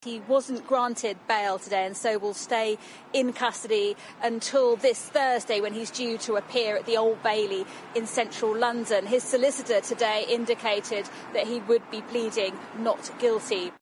is at Willesden Magistrates’ Court in north west London.